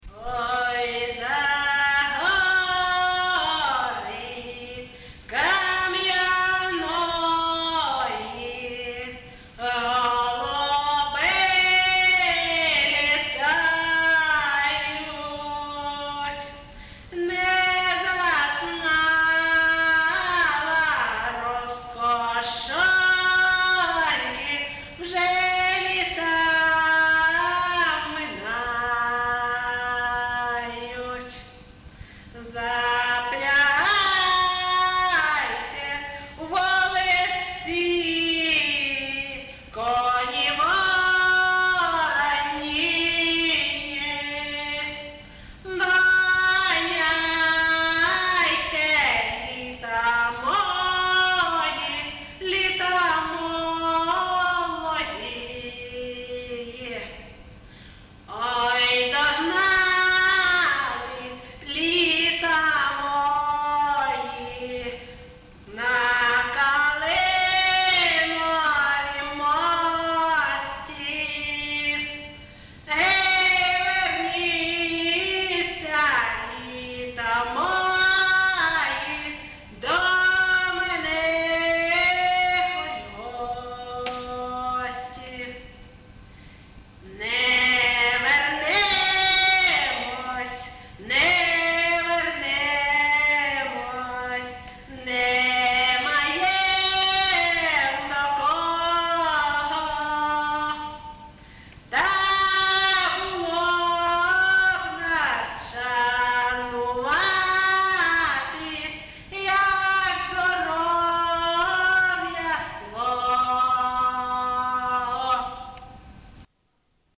ЖанрПісні з особистого та родинного життя
МотивЖурба, туга
Місце записус. Ковалівка, Миргородський район, Полтавська обл., Україна, Полтавщина